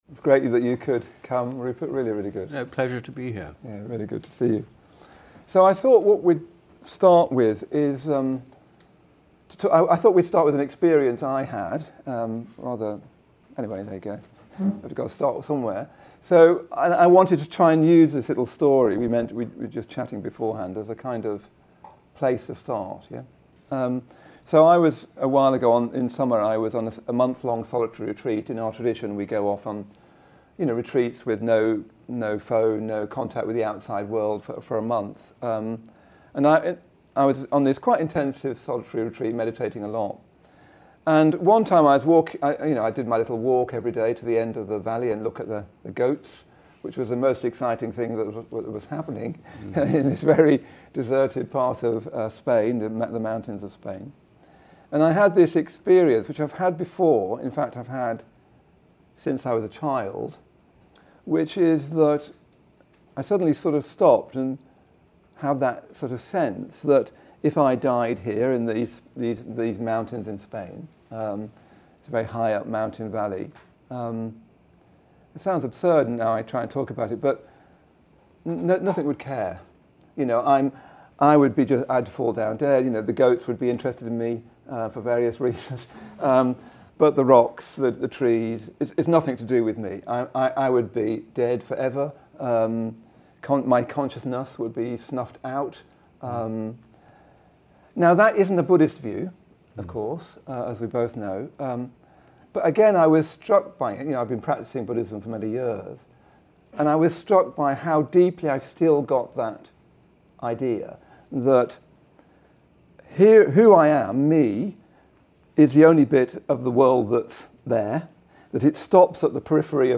Rupert Sheldrake, biologist and author best known for his hypothesis of morphic resonance, joins PhilosophyEAST to discuss the dogmas of modern science, the true nature of consciousness and the limits of materialism.